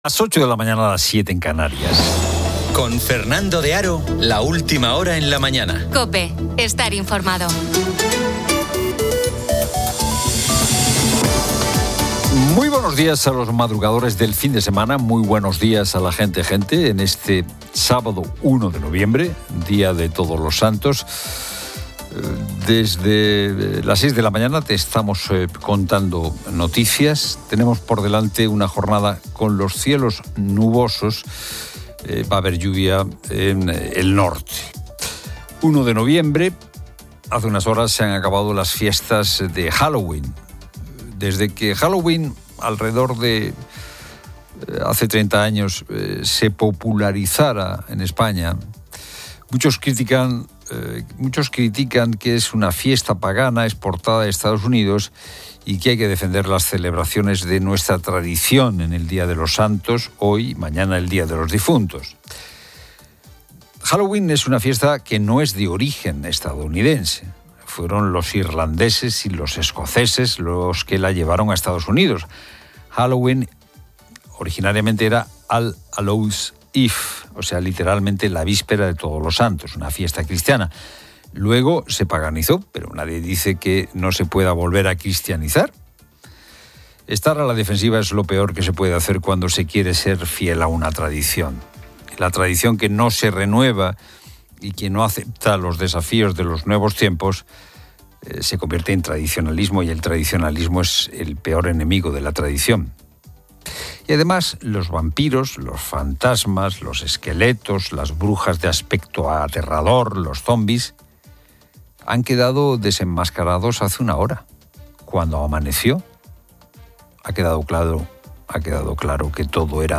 Fernando de Haro presenta la última hora en COPE.